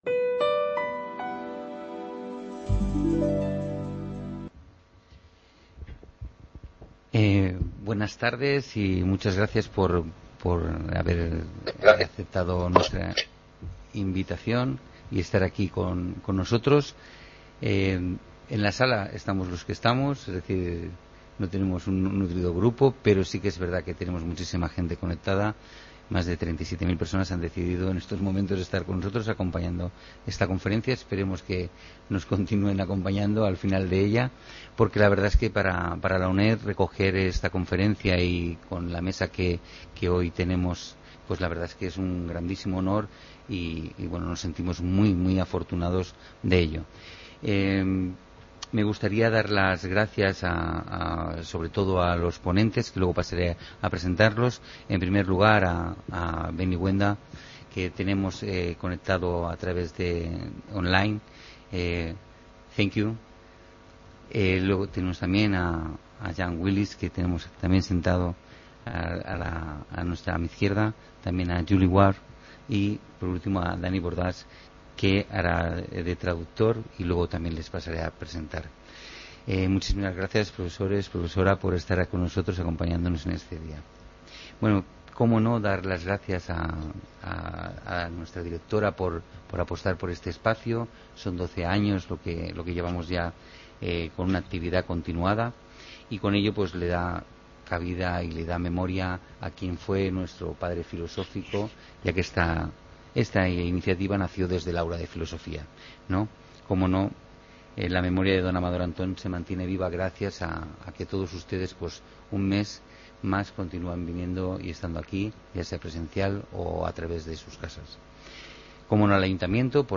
Conferencia Blanca